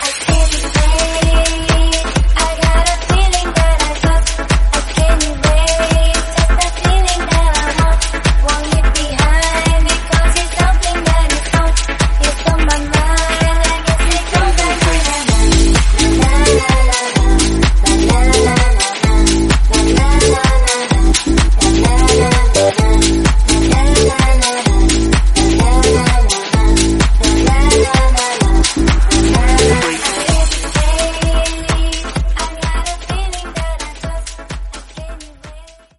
Genre: RE-DRUM Version: Clean BPM: 160 Time